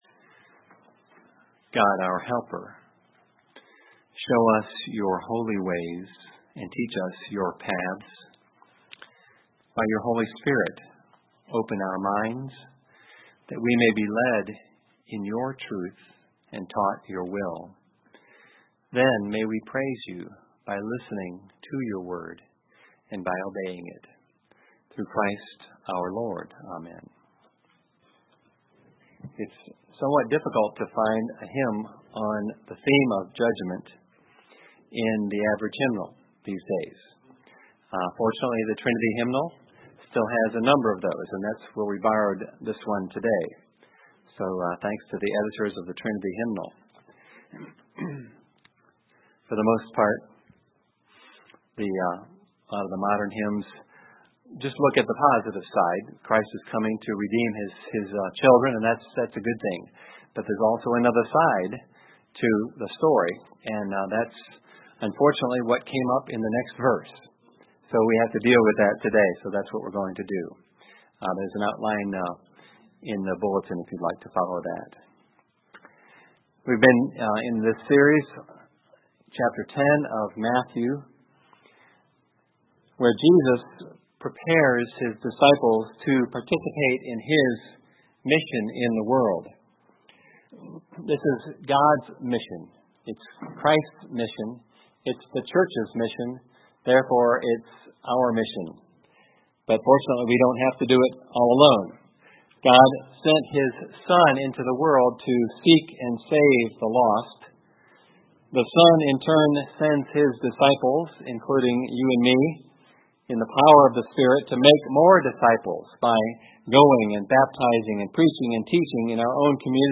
In our passage today, Jesus tells them what will happen to those who will reject and disobey their message as both a word of encouragement to his disciples and a word of warning to the unrepentant. Sermon Text: Matthew 10:14-15 14 And if anyone will not receive you or listen to your words… 15 Truly, I say to you, it will be more bearable on the day of judgment for the land of Sodom and Gomorrah than for that town.